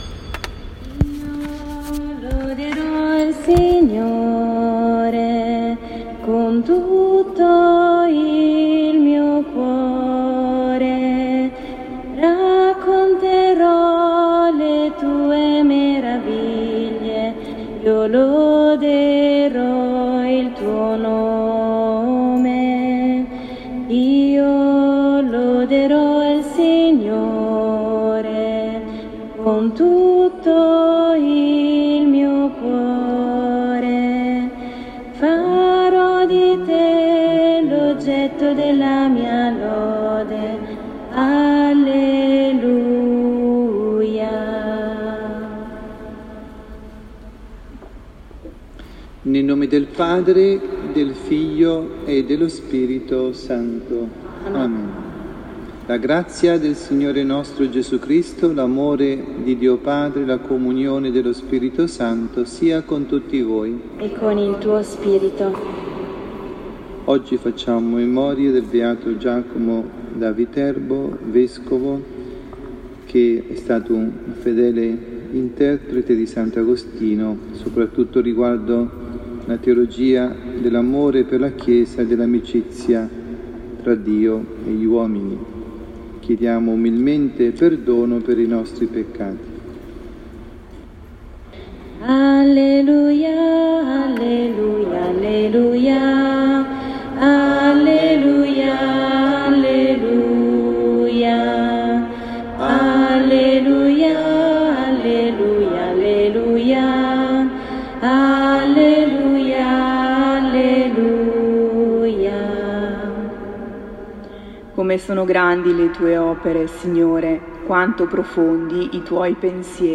dalla Parrocchia S. Rita – Milano